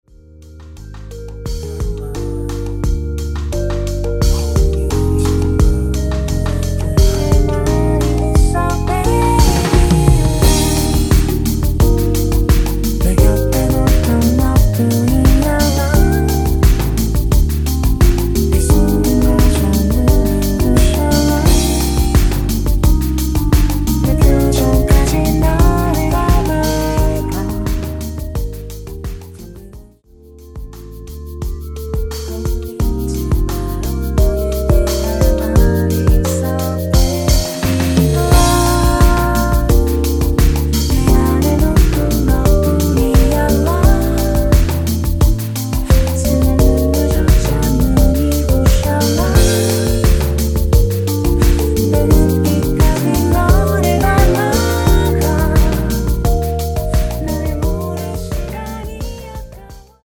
엔딩이 페이드 아웃이라 노래 끝나고 바로 끝나게 엔딩을 만들어 놓았습니다.(원키 멜로디 MR 미리듣기 확인)
원키에서(-1)내린 멜로디와 코러스 포함된 MR입니다.(미리듣기 확인)
Eb
앞부분30초, 뒷부분30초씩 편집해서 올려 드리고 있습니다.